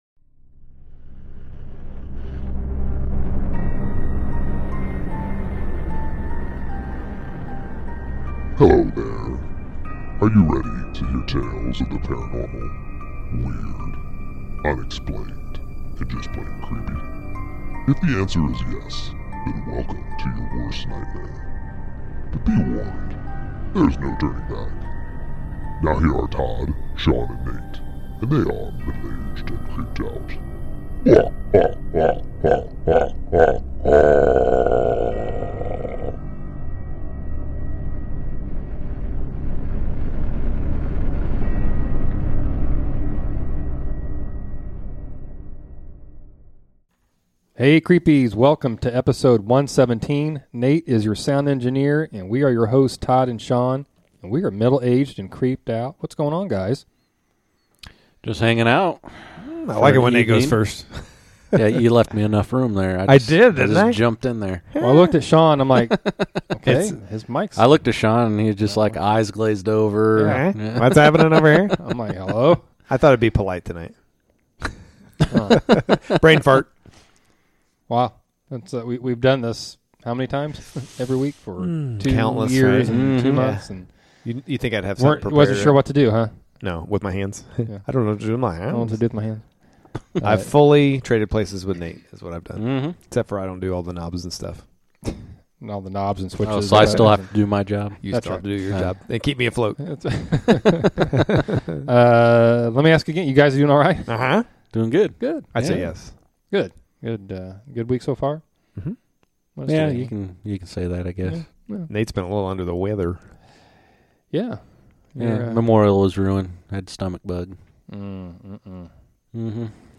Guest Chat